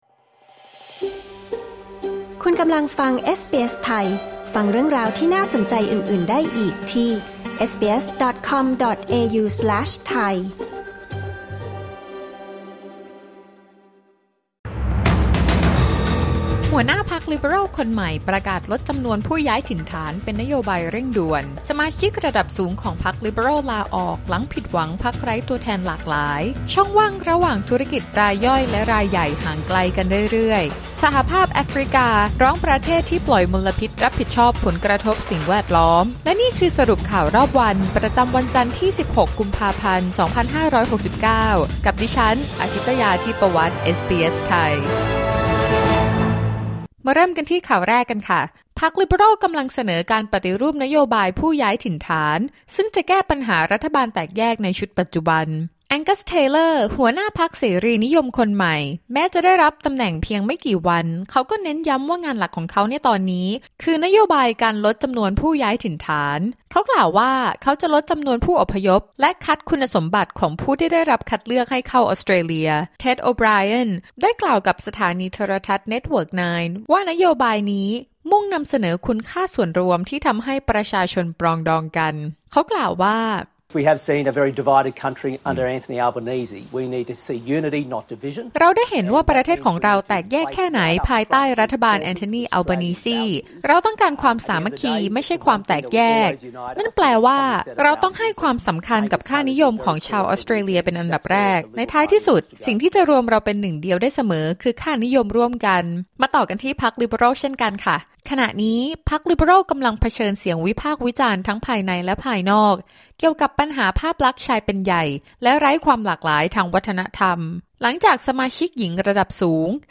สรุปข่าวรอบวัน 16 มกราคม 2569